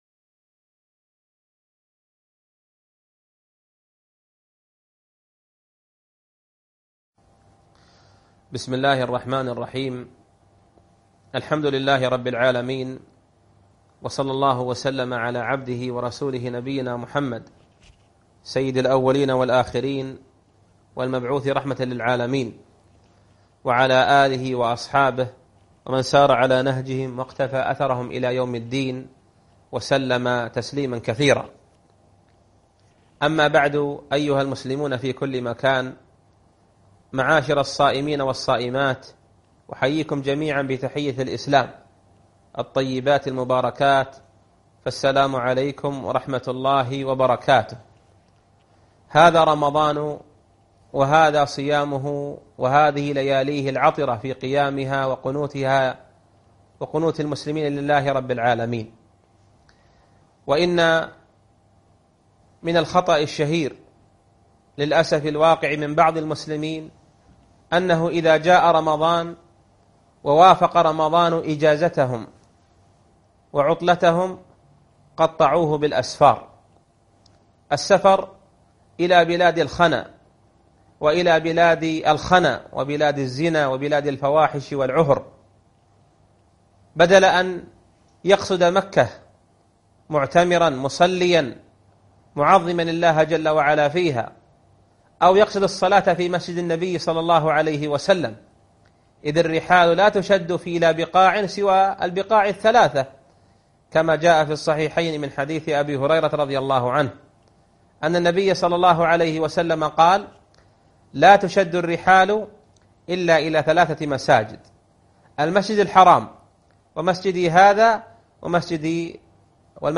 عنوان المادة الدرس 15 (السفر في رمضان الى الحرم) فقه الصيام